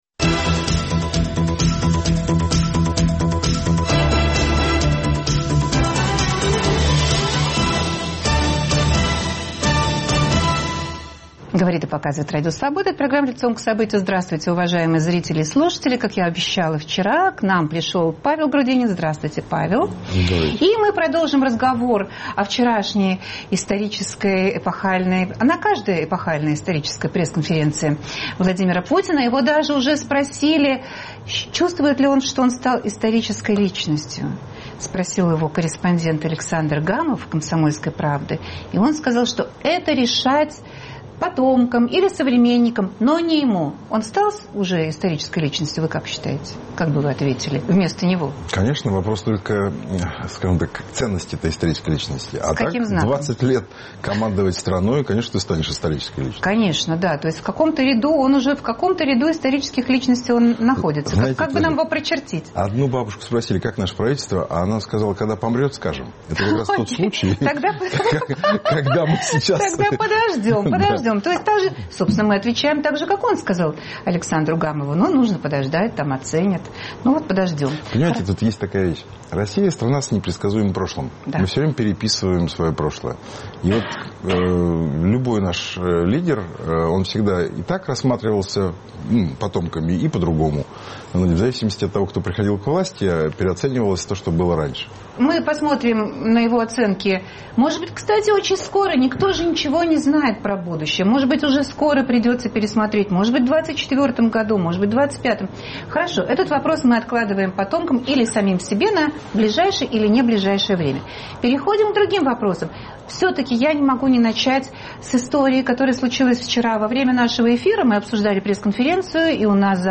Гость программы "Лицом к событию" – Павел Грудинин.